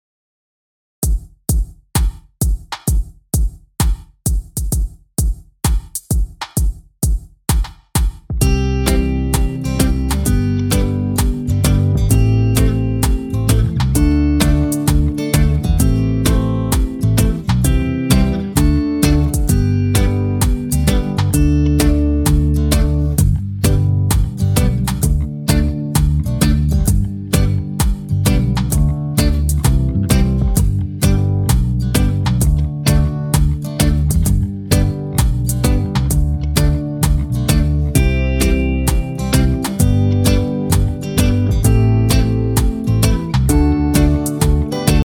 Downloadable Instrumental Track